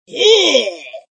SAmbalAlarm.ogg